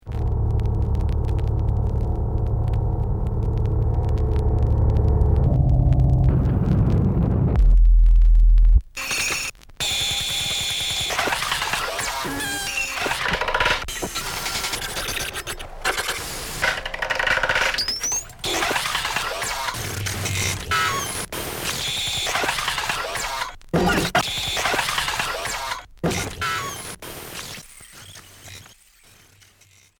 Expérimental